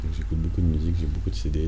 schwa_beaucoup de musique.wav